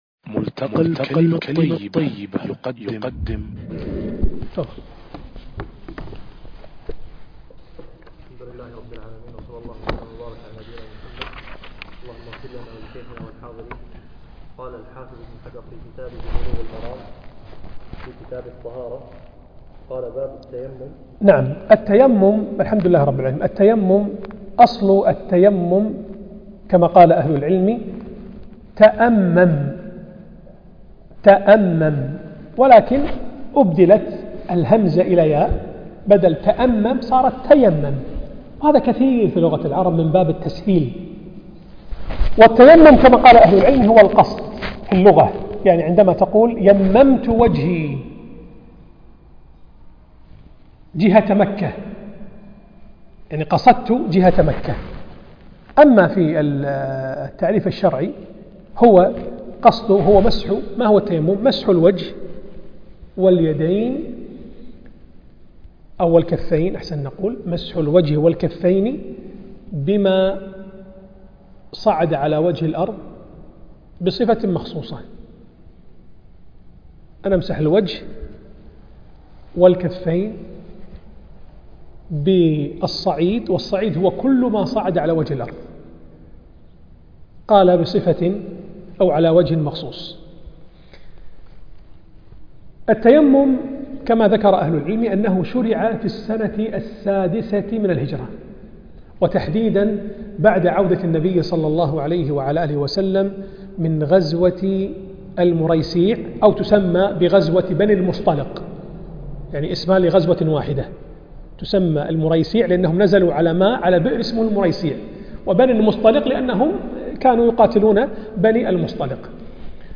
الدرس (13) شرح كتاب بلوغ المرام